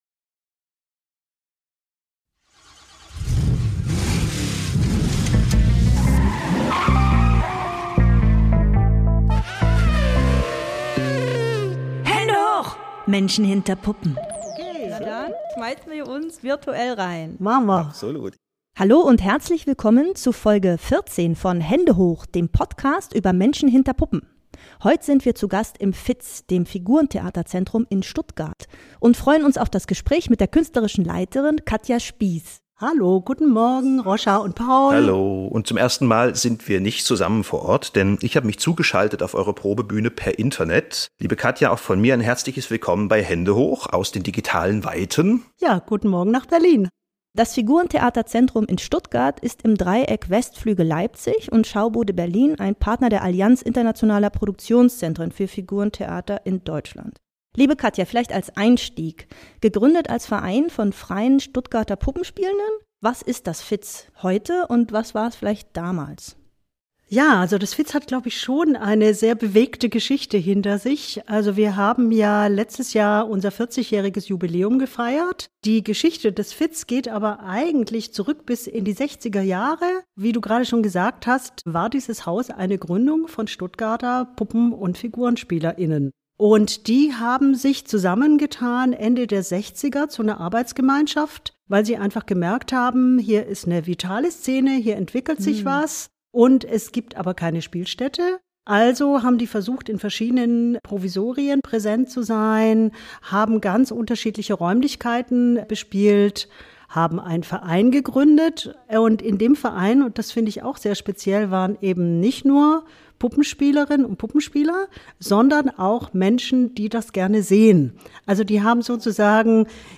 Mit Verzögerung und allem drum und dran.